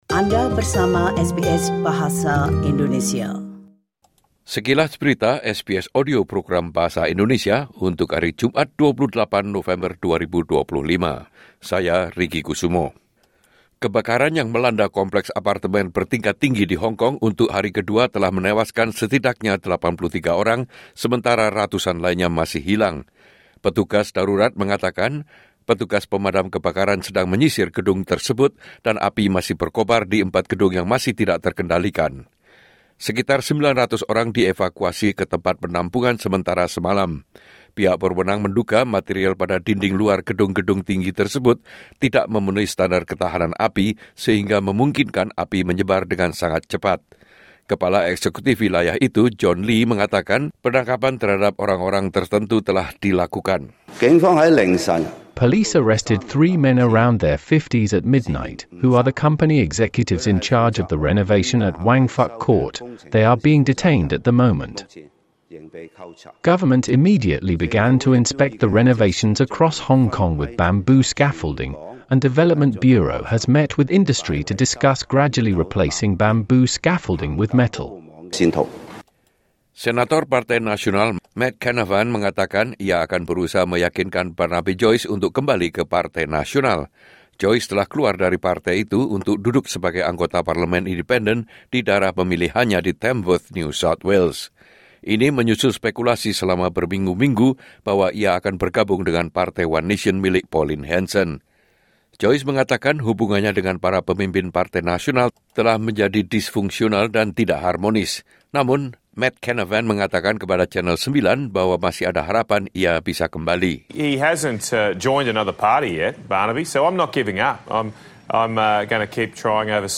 Berita terkini SBS Audio Program Bahasa Indonesia - Jumat 28 November 2025